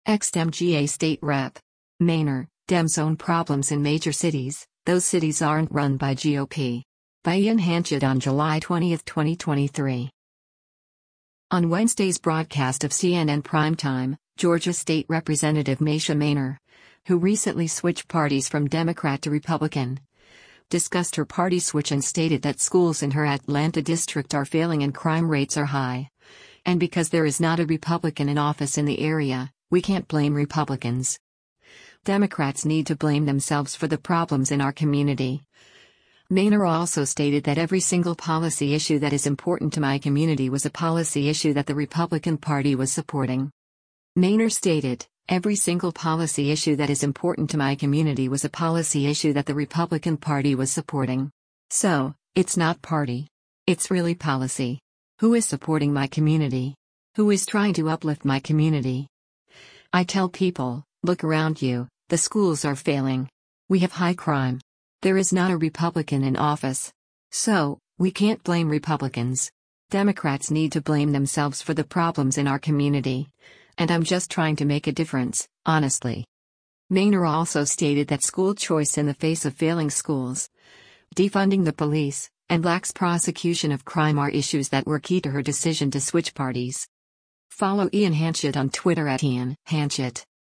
On Wednesday’s broadcast of “CNN Primetime,” Georgia State Rep. Mesha Mainor, who recently switched parties from Democrat to Republican, discussed her party switch and stated that schools in her Atlanta district are failing and crime rates are high, and because “There is not a Republican in office” in the area, “we can’t blame Republicans. Democrats need to blame themselves for the problems in our community,” Mainor also stated that “Every single policy issue that is important to my community was a policy issue that the Republican Party was supporting.”